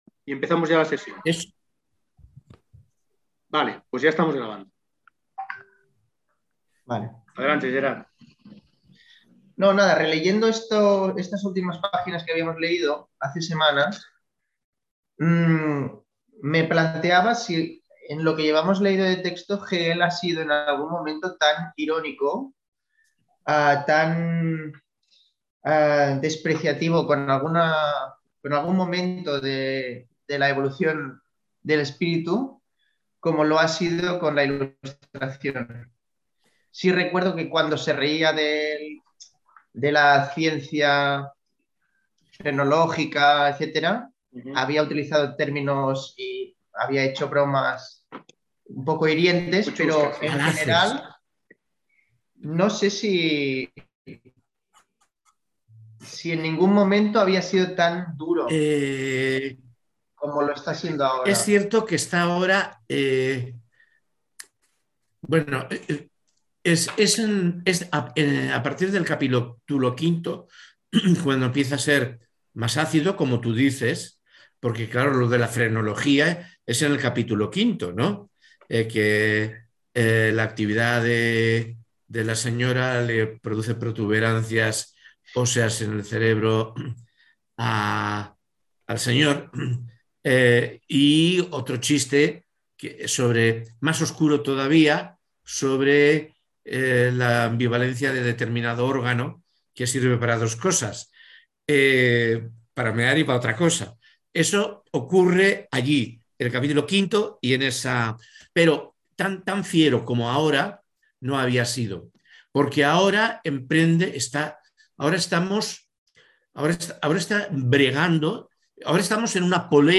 No obstante, con el inicio de la pandemia, empezaron a realizarse también en línea. Fue entonces cuando empezamos la grabación de las sesiones de uno de los seminarios en curso, el dedicado a Fenomenología del espíritu de Hegel.